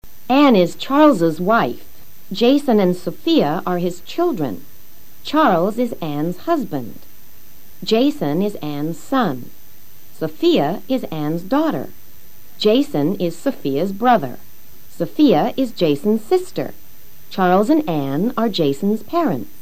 Escucha al profesor leyendo oraciones sobre los parentescos que puedes ver en la imagen.